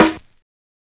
Snare (33).wav